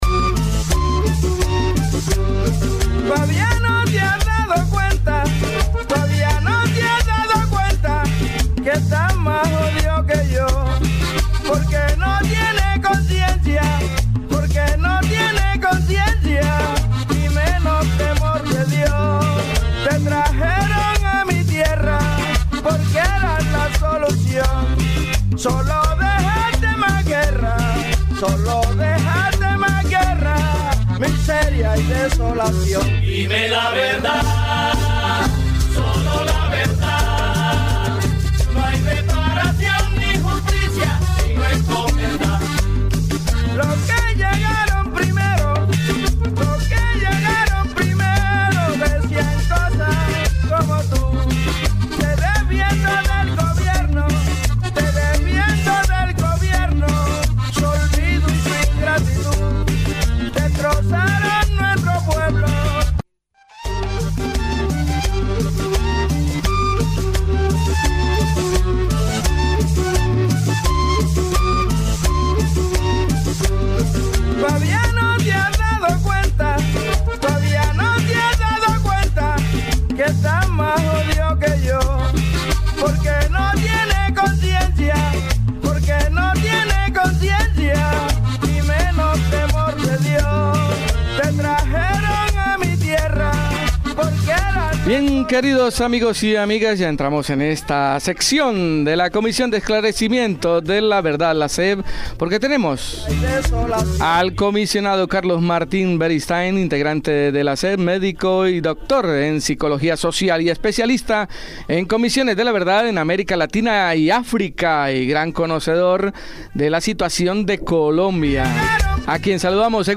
En el Espacio de la Comisión de Esclarecimiento de la Verdad-CEV de Colombia participa el Comisionado Carlos Beristain quien nos habló del significado del Reconocimiento a las Víctimas, el trabajo de la Comisión y los 6 Encuentros realizados en estos dos años de testimonios, a su vez un pequeño homenaje al comisionado Alfredo Molano a un año de su fallecimiento. Nos presenta Carlos una semblanza de Alfredo Molano.